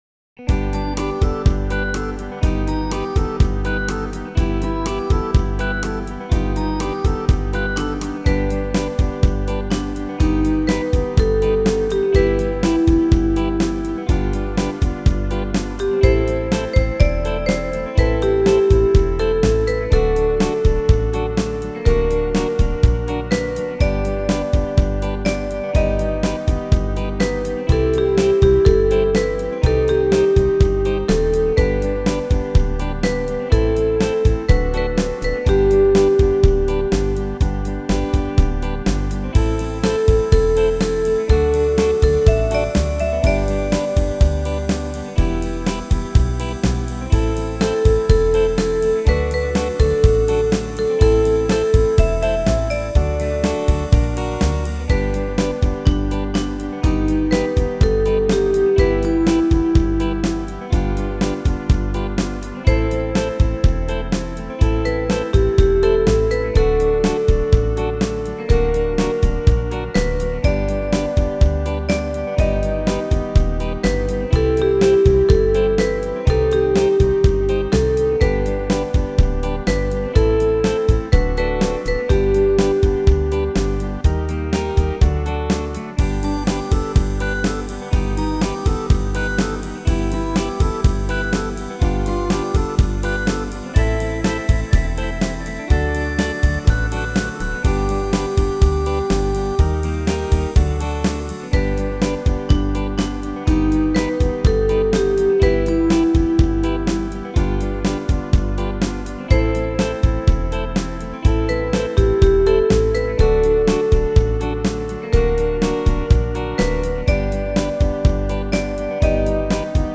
warm ballad